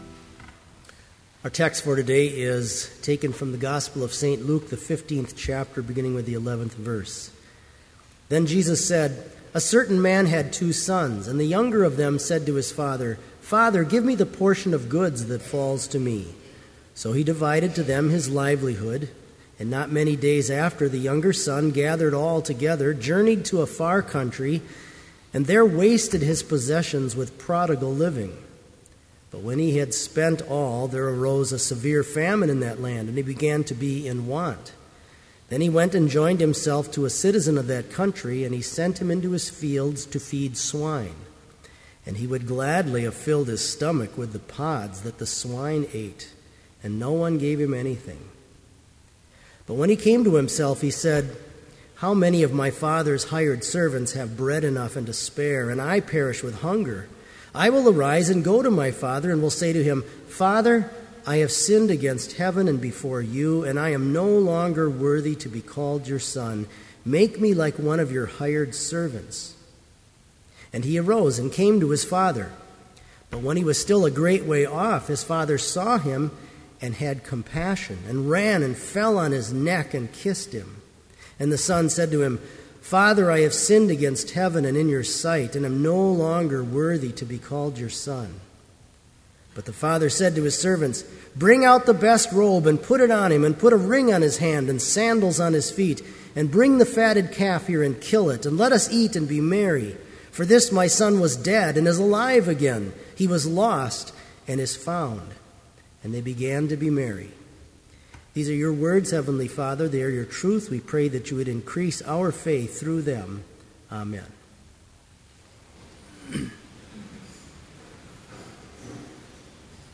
Complete service audio for Summer Chapel - August 22, 2012